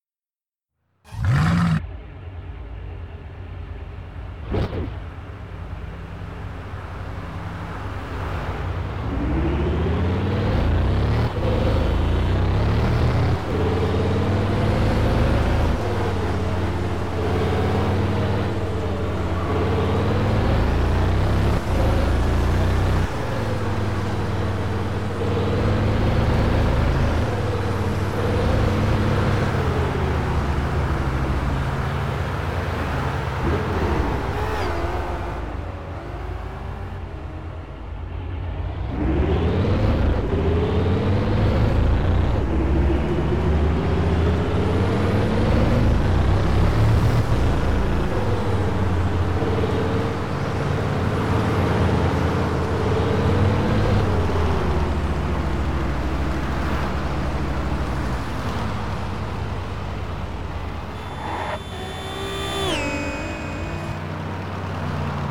- Bugatti Chiron